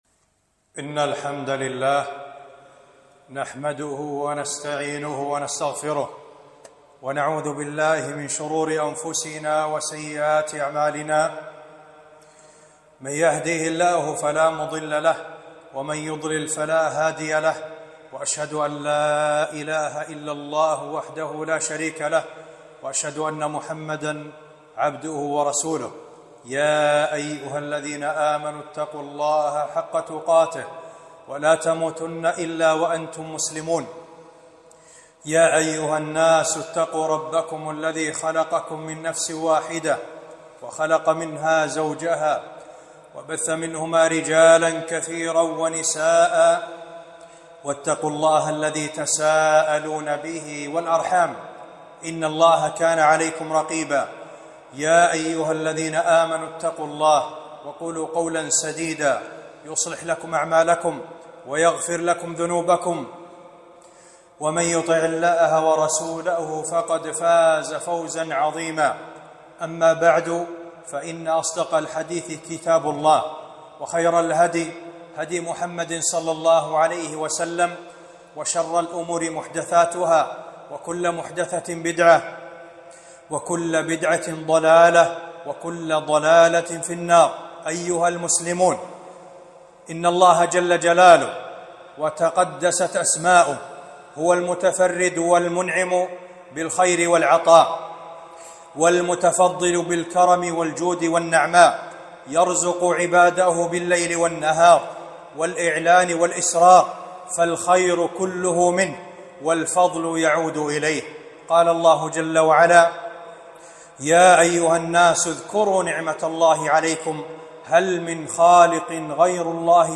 خطبة - التحدث بنعم الله عز وجل